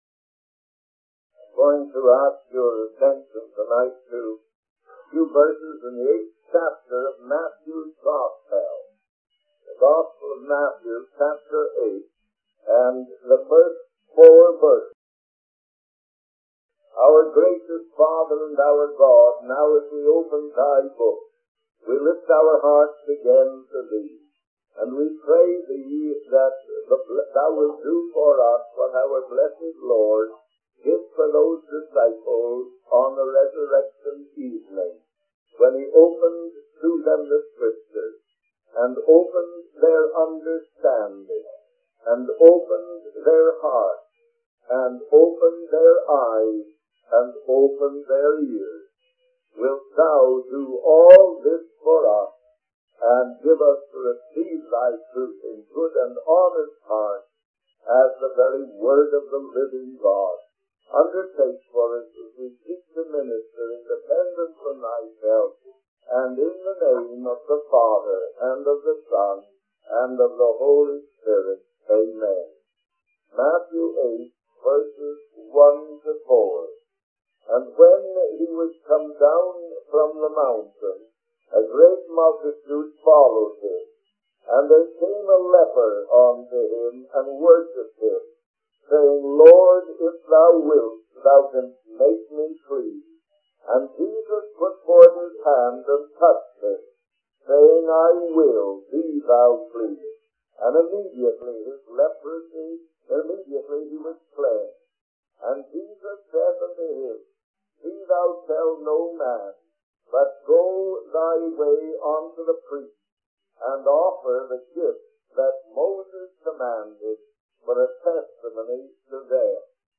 In this sermon, the preacher begins by referencing the Gospel of Matthew chapter 8, specifically the first four verses. He prays for God to open the hearts, understanding, eyes, and ears of the listeners, just as Jesus did for his disciples.